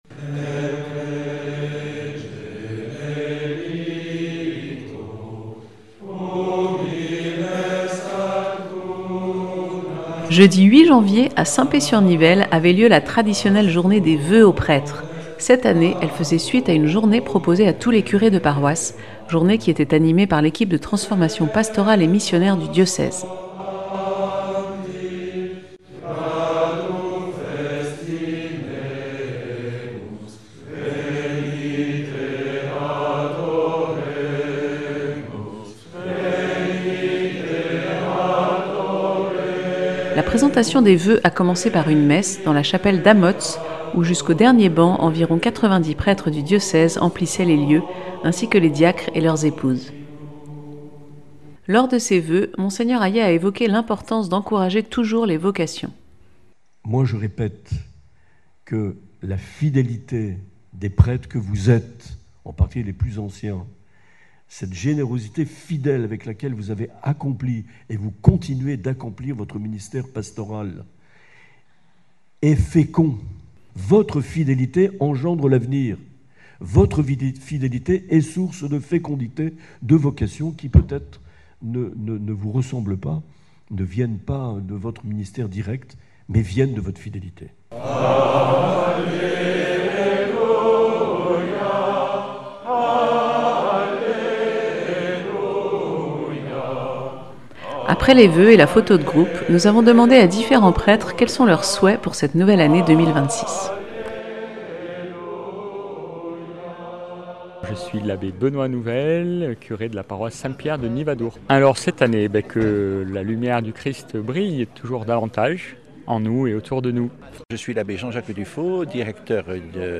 Mgr Marc Aillet a présenté ses voeux au clergé jeudi 8 janvier à St Pée sur Nivelle, dans la chapelle d’Amotz.